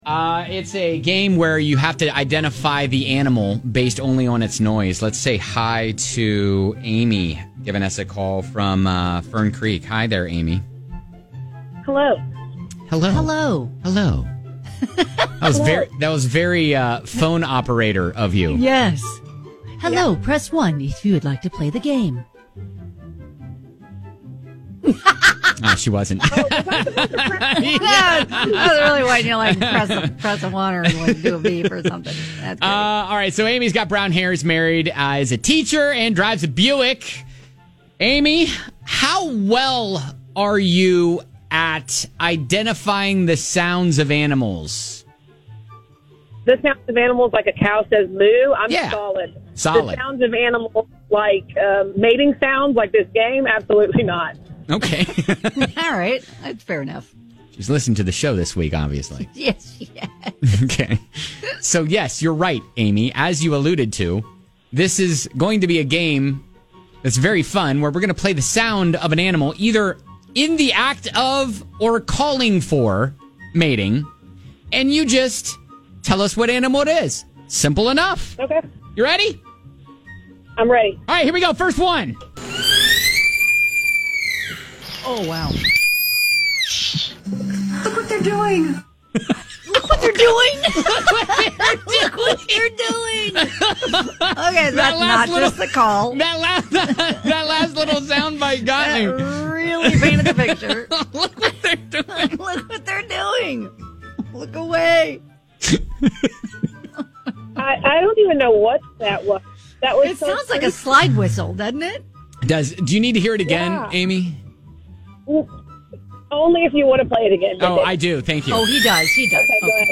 Guess The Animal Based on Its Mating Noise